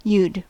Ääntäminen
France: IPA: /vu/